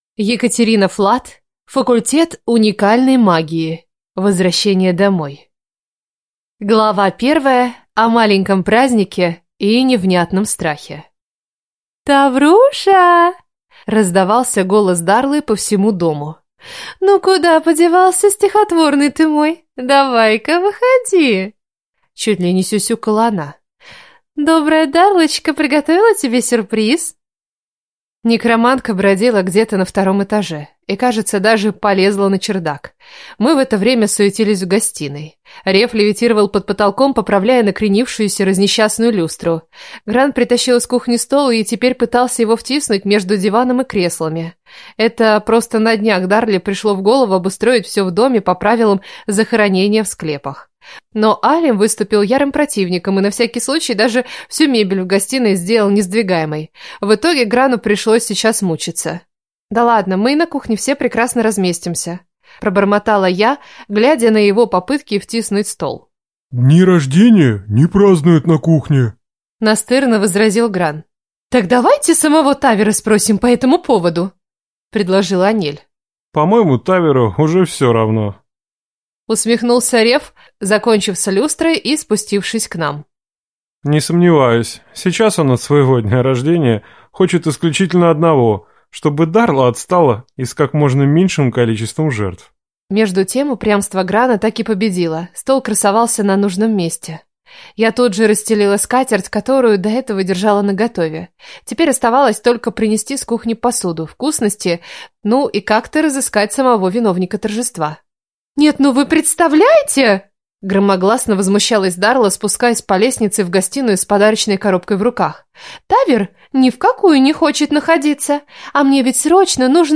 Аудиокнига Факультет уникальной магии. Возвращение домой | Библиотека аудиокниг